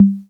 Index of /90_sSampleCDs/Roland L-CD701/DRM_Drum Machine/KIT_CR-78 Kit
PRC CR78 L0B.wav